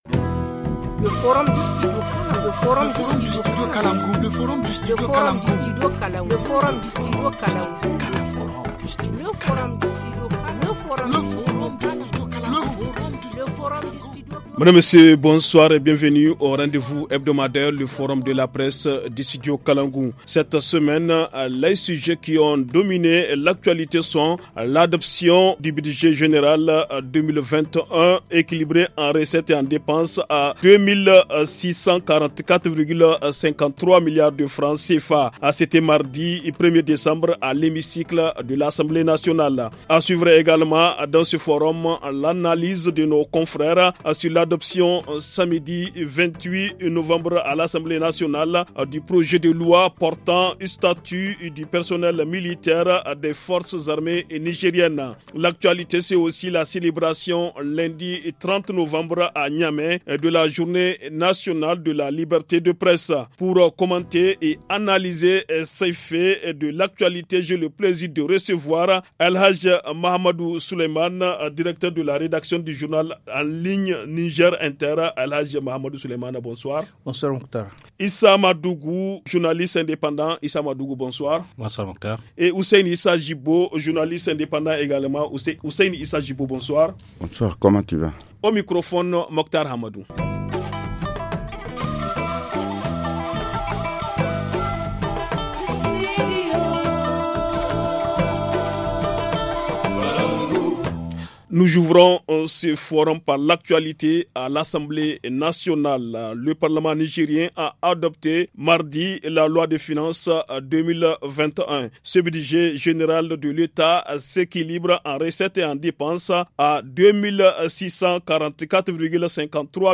Pour commenter et analyser tous ces faits de l’actualité, j’ai le plaisir de recevoir :